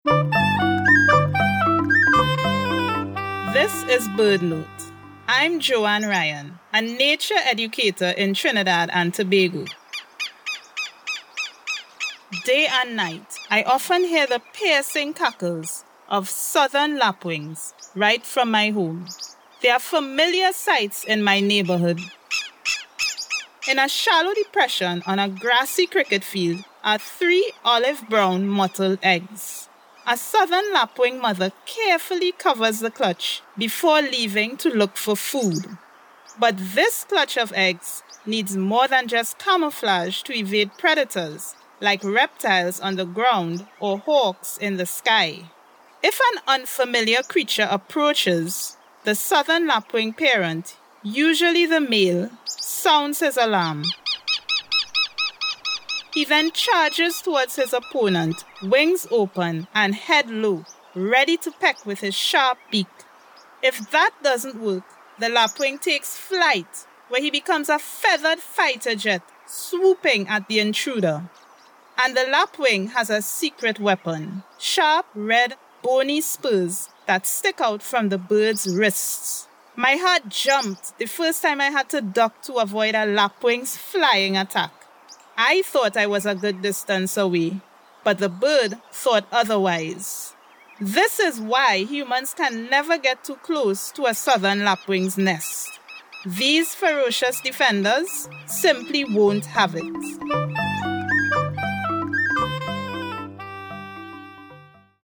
Nature educator